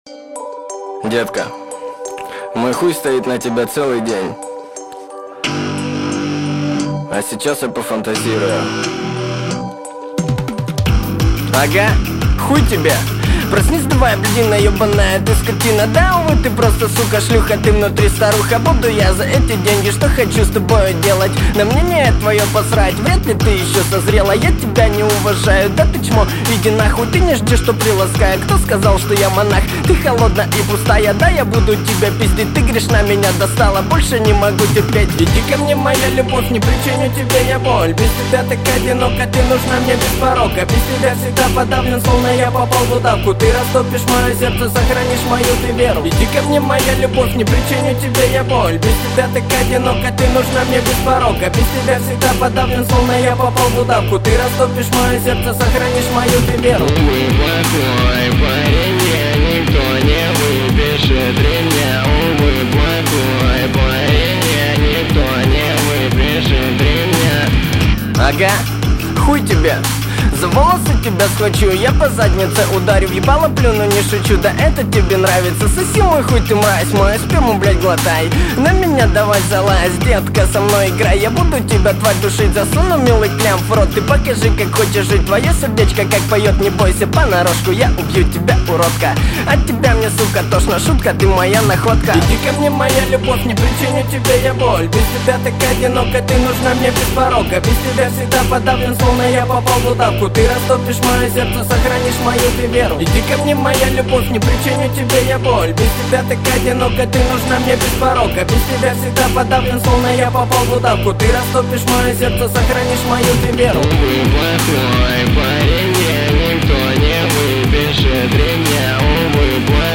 Музыкальный хостинг: /Рэп